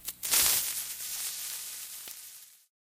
tnt_ignite.ogg